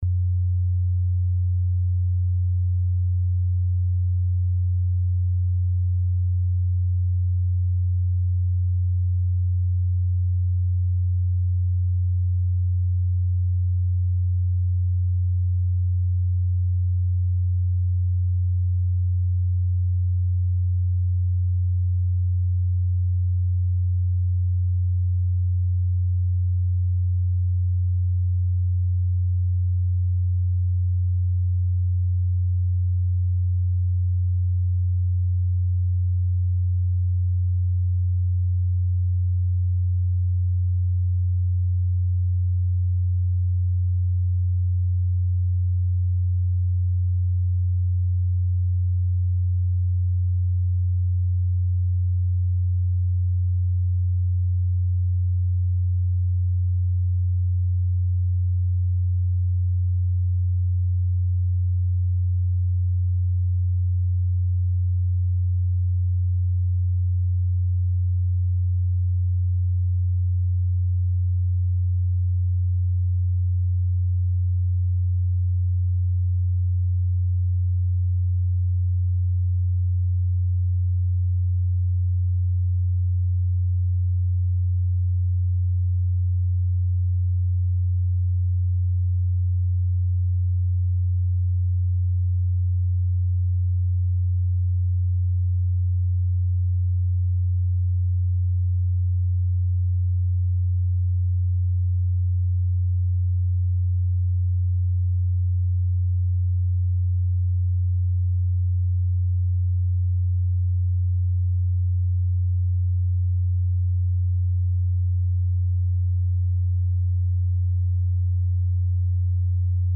Left at 90Hz - sample - this is just a simple sine wave at 90Hz in the left audio channel.
lft_90hz_5.mp3